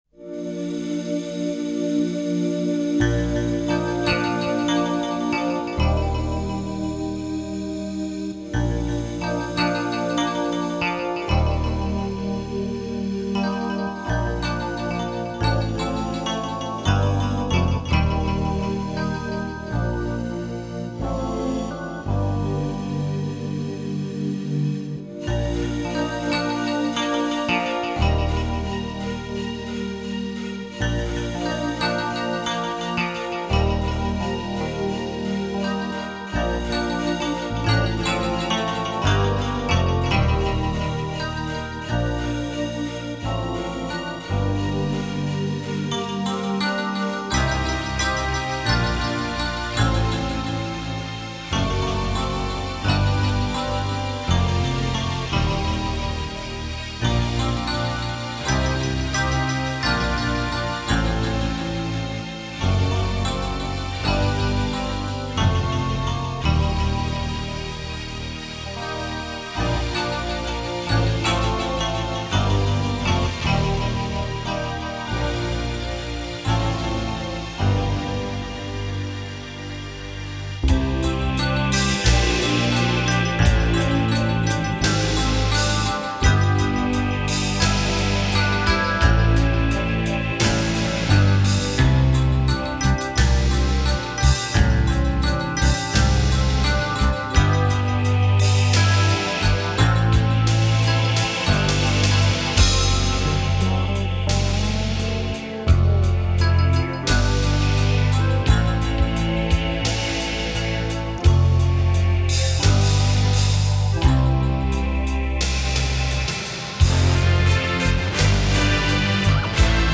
pomalu, sólo e. piano